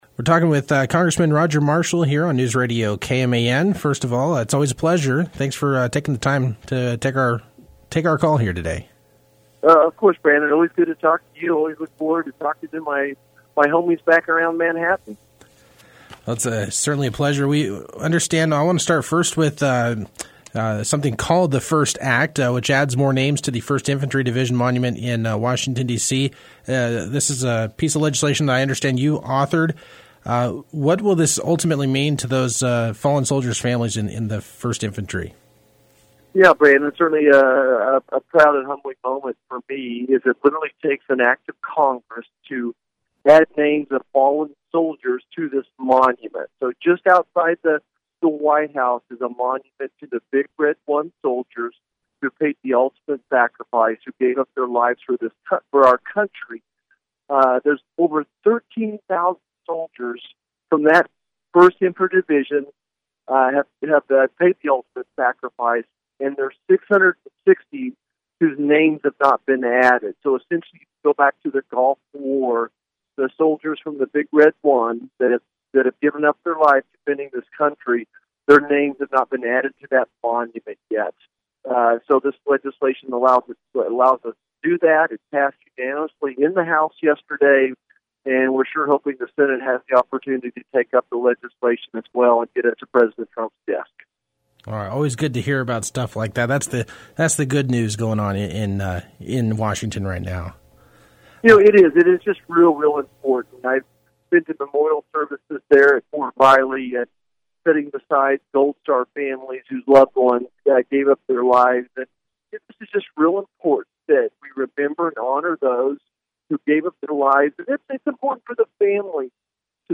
Listen to the full interview with Congressman Roger Marshall below.